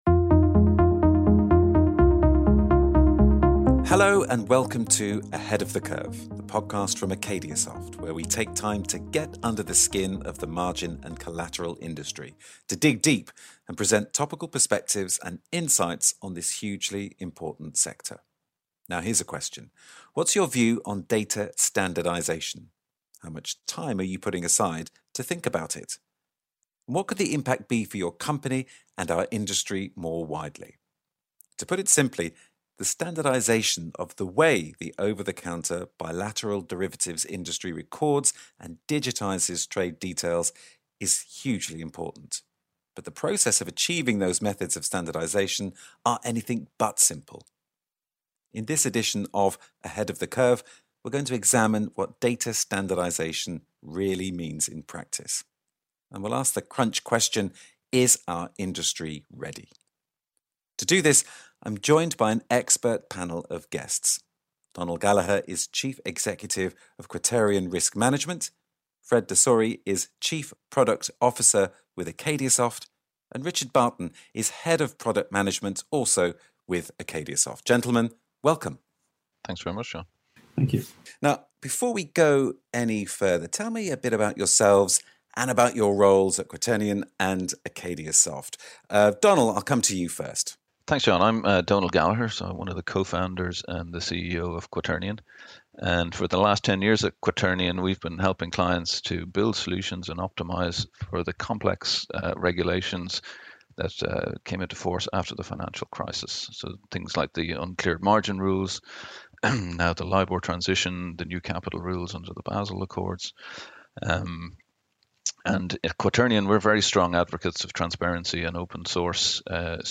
They provide fresh insights into how data standards in OTC Derivatives can help to mitigate risk. Taking us on a journey that spans the evolution of data standardization from the financial crisis in 2008 to present day with some positive predictions for the future.Produced and Recorded by Lansons remotely.